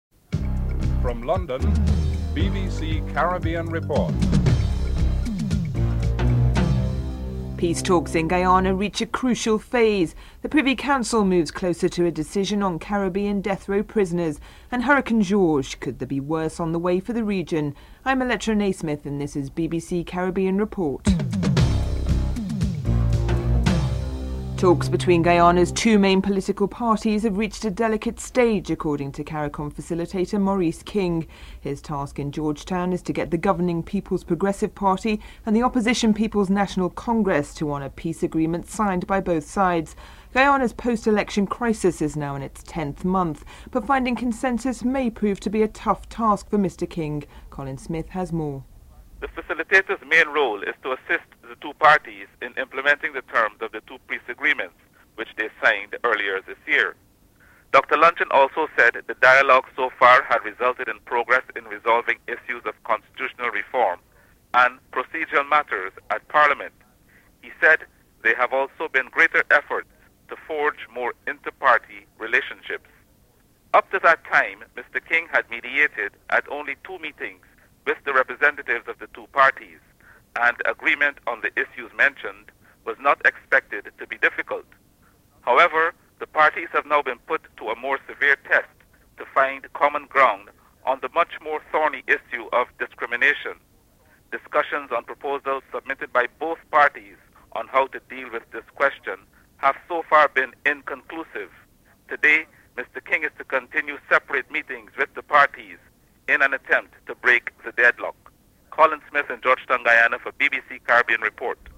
5. One of Britain's senior government officials is in Cuba on a two day visit. Baroness Symons Parliamentary Under Secretary in the Foreign and Commonwealth Office says that is the first trip to the island in many years for the Labour Administration.